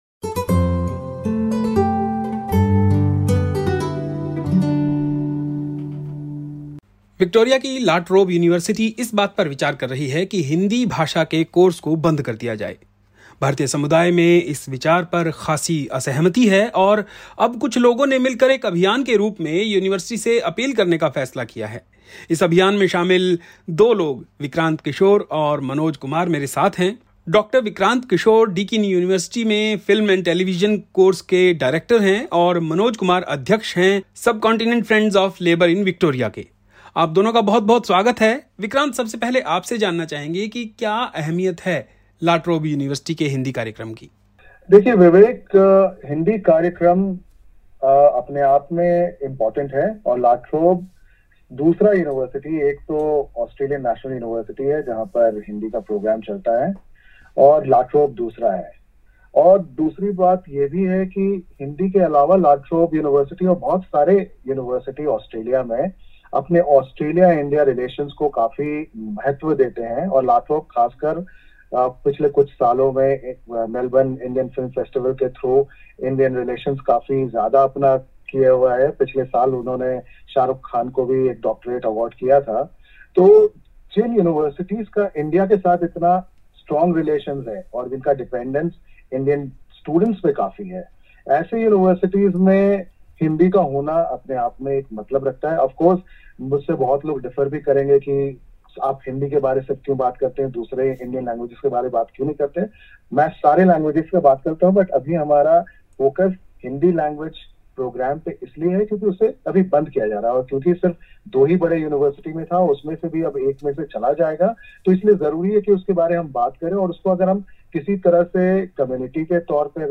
Listen to the Hindi report here: